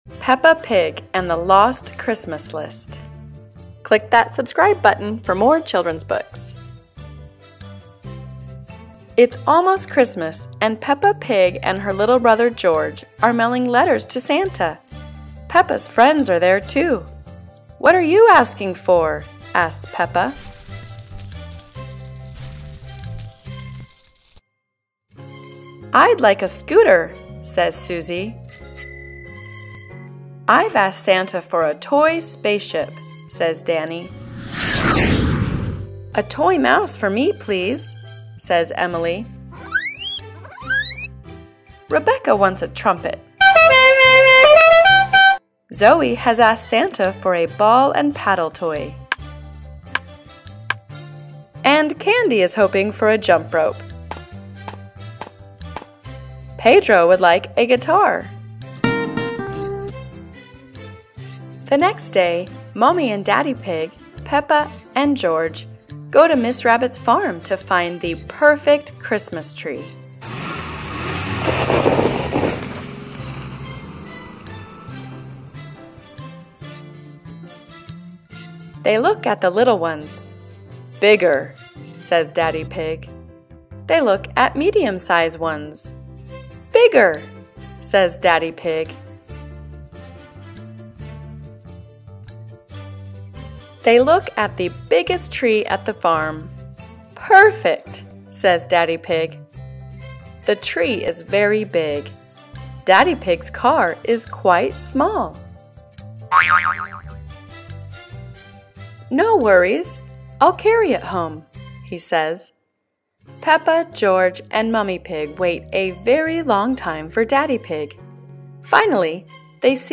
دانلود کتاب داستان صوتی Peppa Pig and the Lost Christmas List